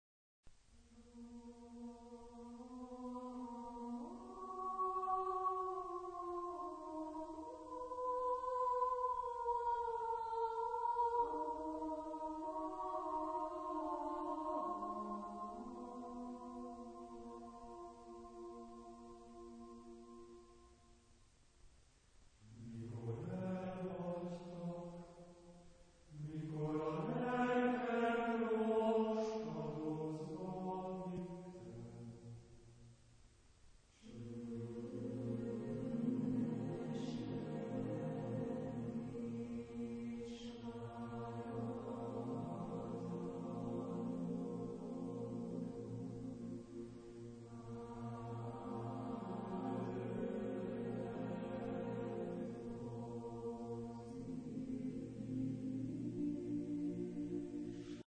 Epoque: 20th century  (1970-1979)
Genre-Style-Form: Secular ; Lyrical ; Choir
Type of Choir: SSATB  (5 mixed voices )
Tonality: modal